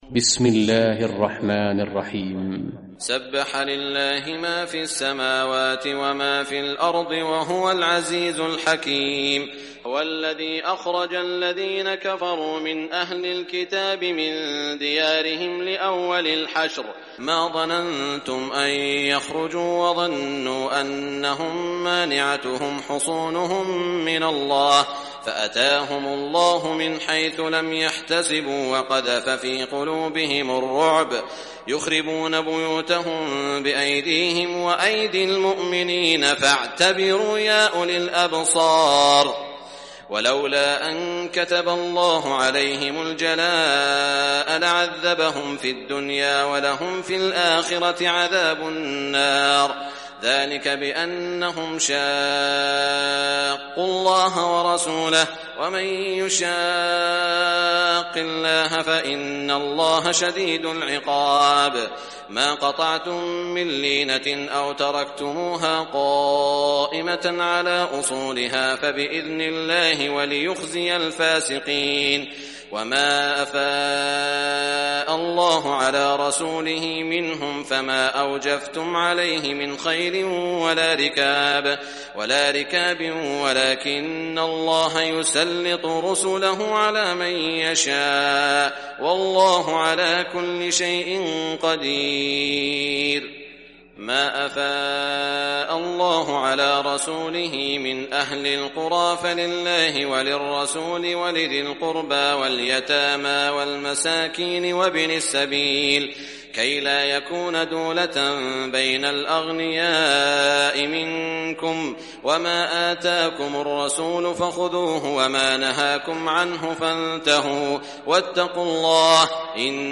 Surah al Hashr Recitation by Sheikh Shuraim
Surah al Hashr, listen or play online mp3 tilawat / recitation in arabic in the beautiful voice of Sheikh Saud al Shuraim.
059-al-hashr.mp3